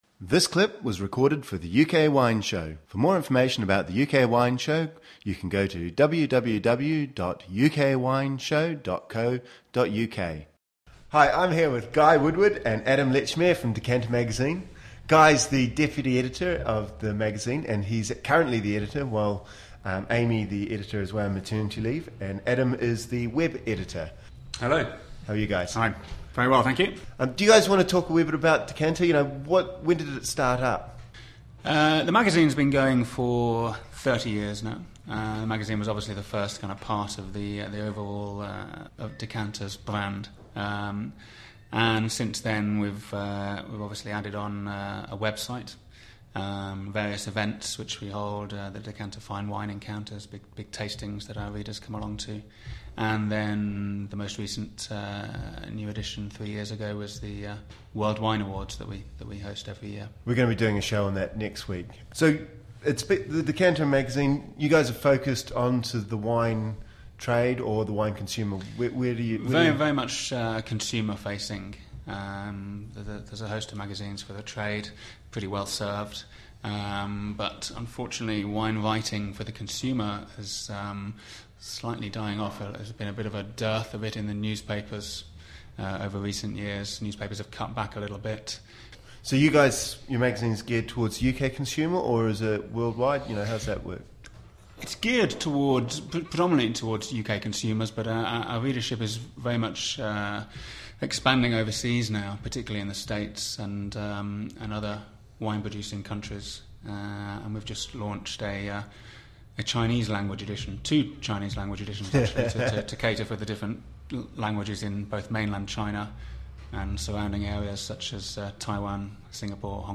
Listen to the full UK Wine Show